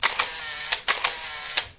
电机驱动器和快门操作
描述：旧的佳能T70 35mm相机，电机驱动器和快门操作。
标签： 摄影 电机驱动 影片 相机 射击 点击 快门
声道立体声